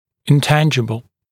[ɪn’tænʤəbl][ин’тэнджэбл]неуловимый, неосязаемый